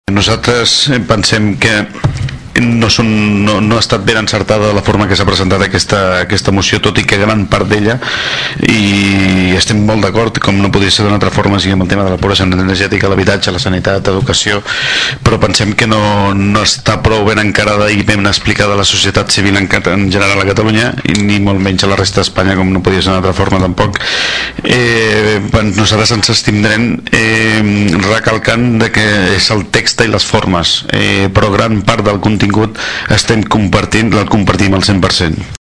El regidor de SOM Tordera, Carles Seijo, va justificar l’abstenció del seu partit al·ludint al text i les formes.